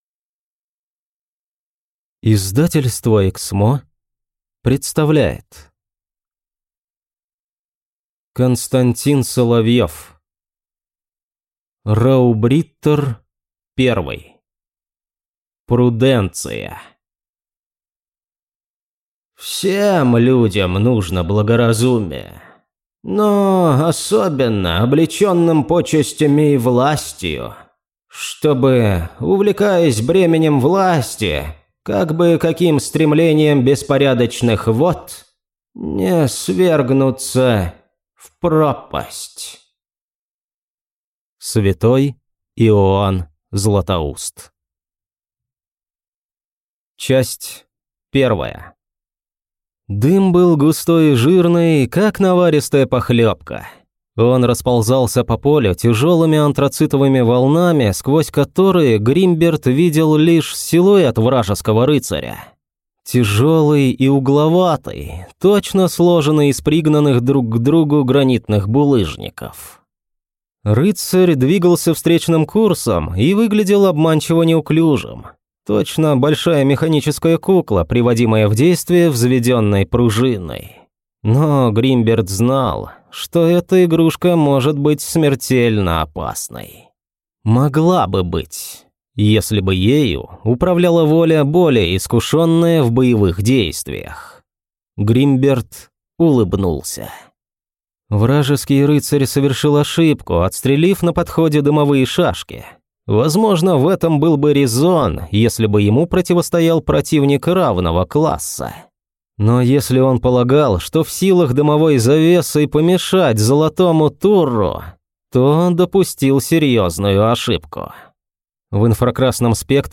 Аудиокнига Раубриттер I. Prudentia | Библиотека аудиокниг